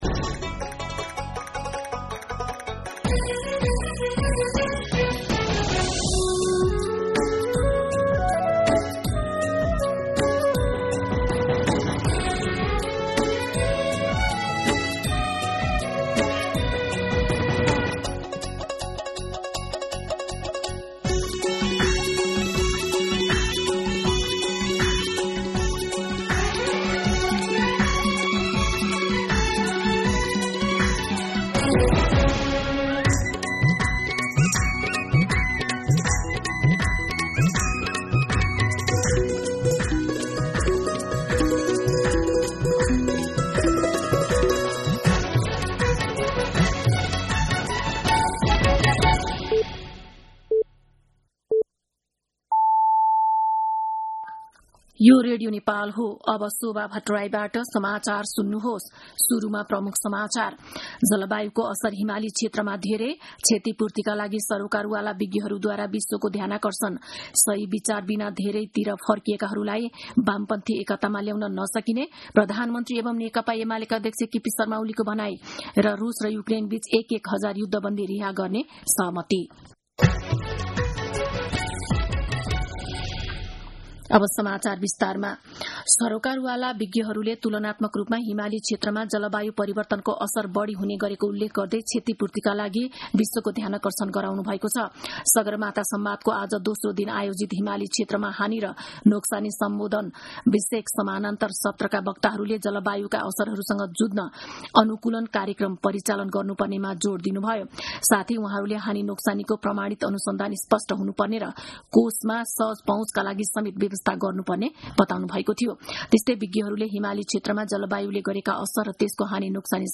दिउँसो ३ बजेको नेपाली समाचार : ३ जेठ , २०८२
3-pm-Nepali-News-3.mp3